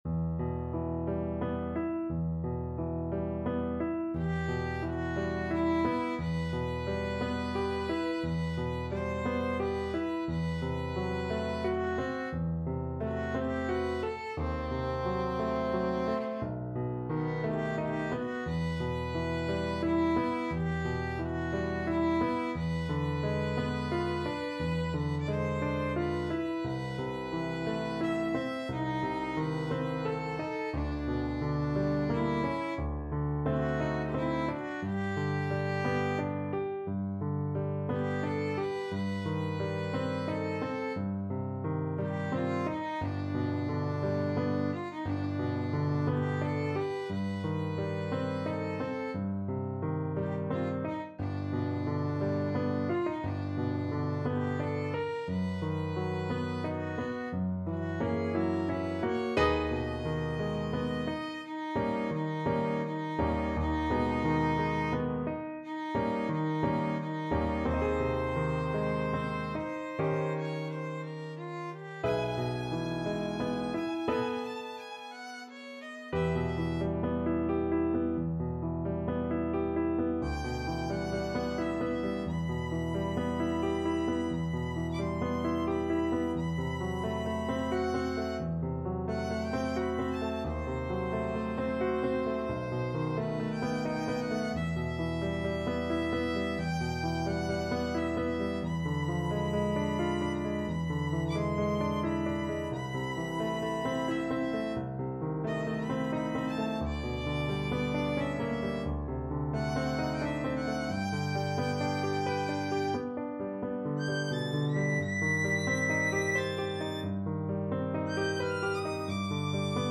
Violin
E minor (Sounding Pitch) (View more E minor Music for Violin )
~ = 88 Malinconico espressivo
3/4 (View more 3/4 Music)
romance-s-169_VLN.mp3